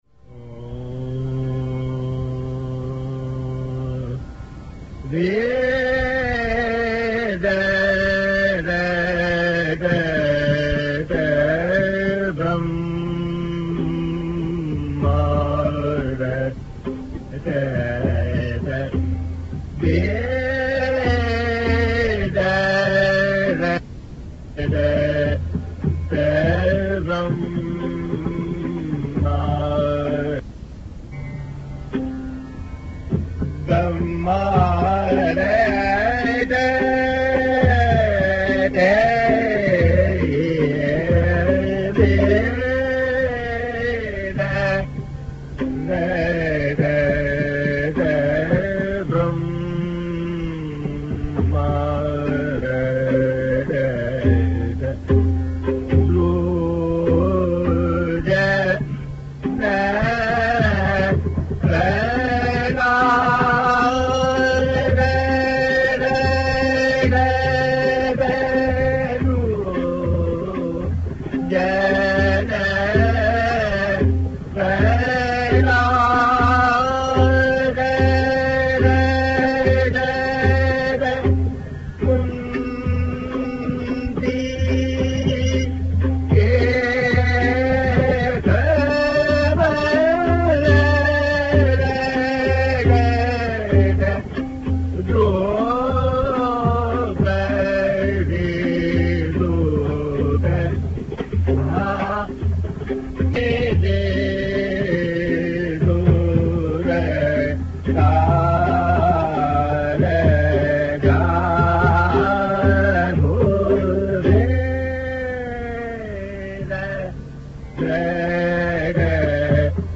R. Fahimuddin Dagar‘s authoritative presence is felt in this dhrupad despite the poor audio quality.